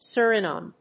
音節Su・ri・na・me 発音記号・読み方
/s`ʊ(ə)rənάːmə(米国英語), s(j)`ʊərɪnˈæm(英国英語)/